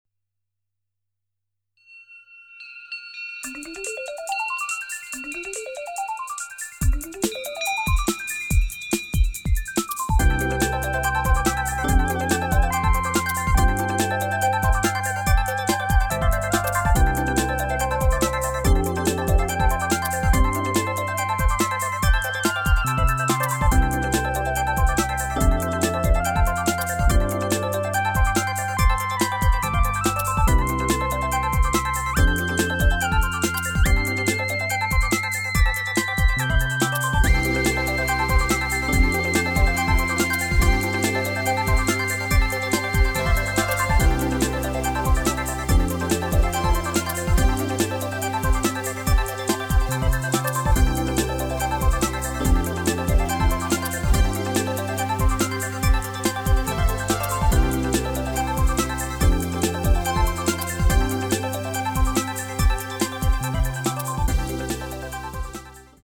・・・いわゆる「パンチの効いた低音」になったような？。